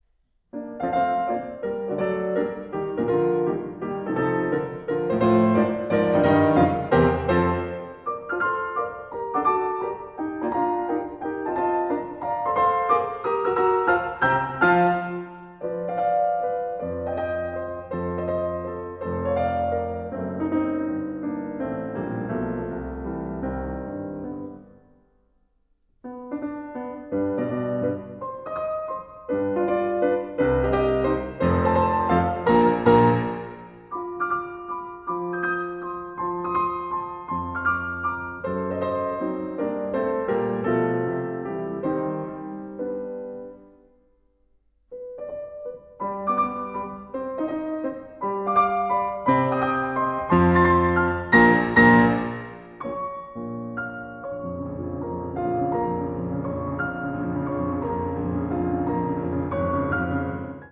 No. 29 - Beethoven 32 Piano Sonatas
The opening theme, again, descends in thirds and there is a clash between B-flat major versus B minor.
The trio is softer and richer and followed by a second trio, the festive Presto, after which the Scherzo returns dolce.